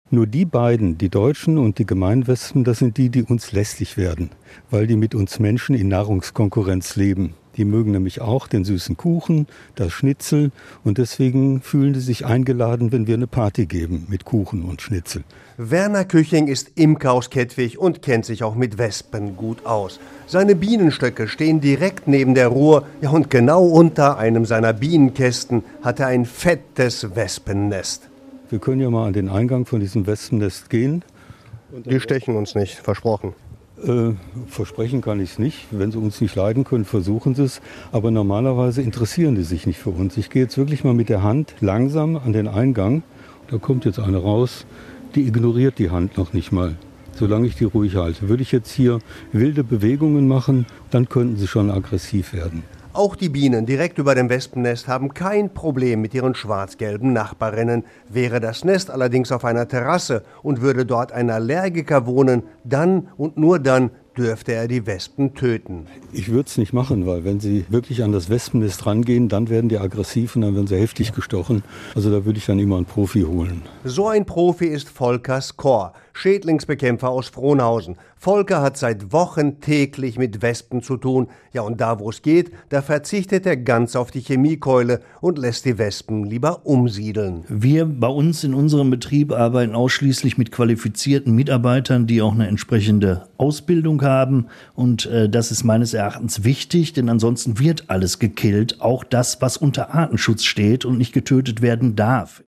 Unser Radio Essen-Stadtreporter hat mit einem Imker über die lästigen Insekten gesprochen.